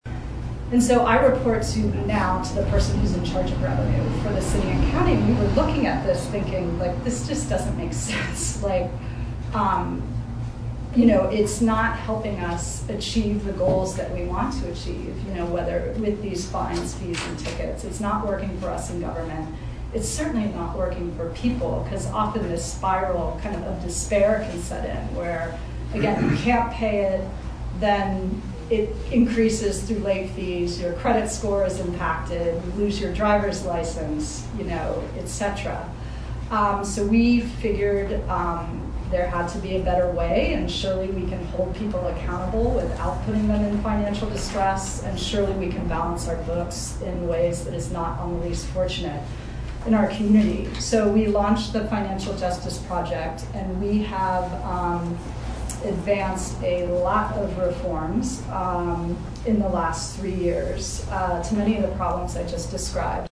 Podcast Special: Marin ACLU Panel Discussion on Criminal Justice Reform
In this podcast, you’ll hear a portion of a panel discussion on criminal justice reform, presented by ACLU of Marin County on October 26, 2019.